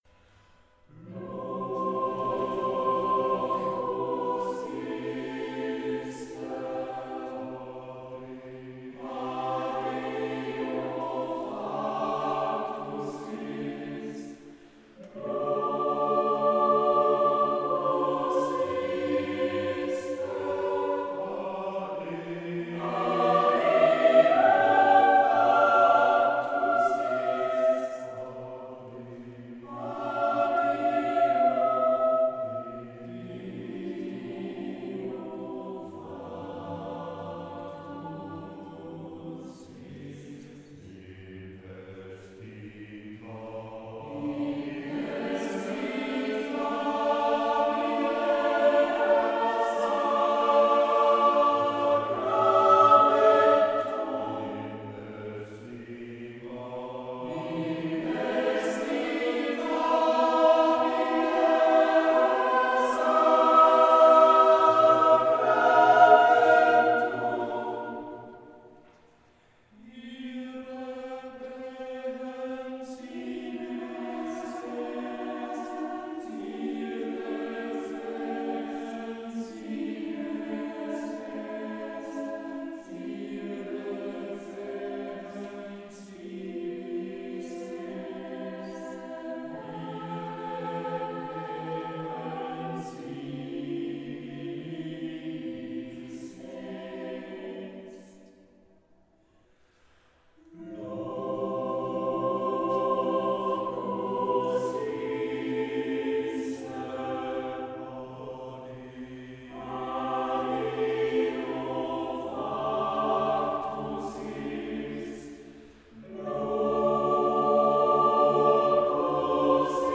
Kirchenchor
romantisch-impressionistischer Chormusik